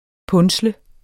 Udtale [ ˈpɔnˀslə ]